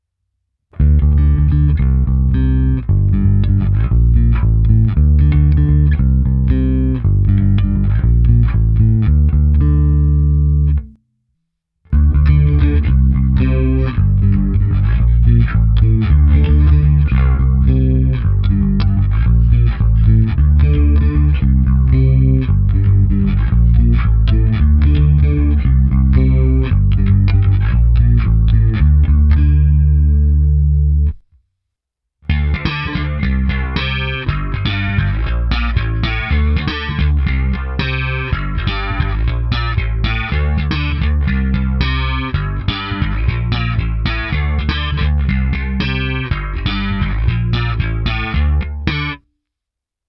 Jedná se chorus speciálně pro baskytaru.
Zvuk chorusu je přirozený, nastavitelný v dostatečném rozsahu, a nastavit pěkný chorus nedá moc laborování.
V první části je neefektovaný zvuk baskytary, následuje ukázka chorusu s prstovou technikou a pak při hraní slapem.